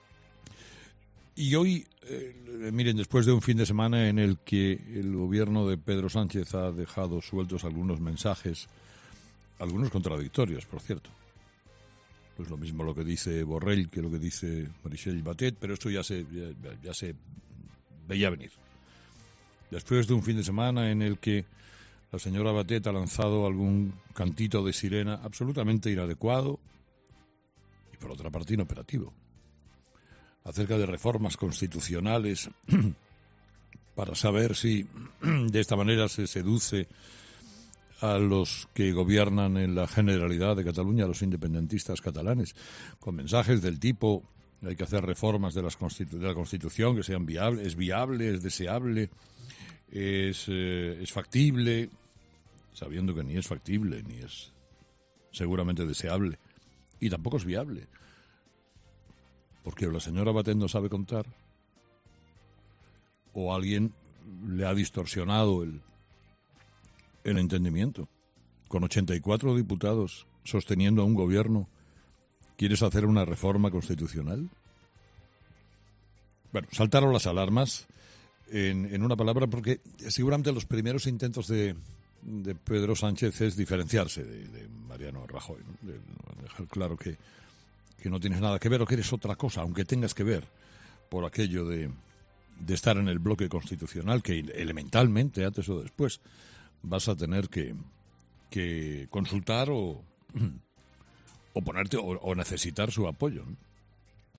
Carlos Herrera ha analizado este lunes en su monólogo la posible reforma constitucional que el nuevo gobierno socialista ya ha planteado para contentar al nuevo Govern catalán.